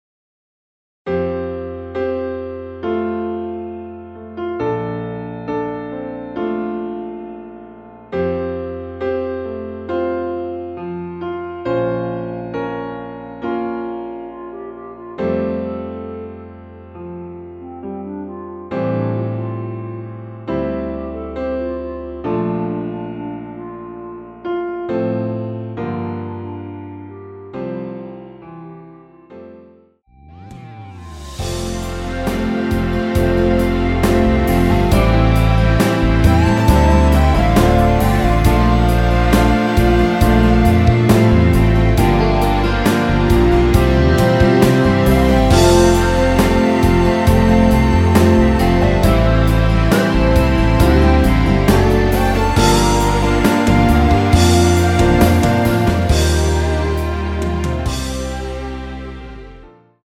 멜로디 MR이라고 합니다.
앞부분30초, 뒷부분30초씩 편집해서 올려 드리고 있습니다.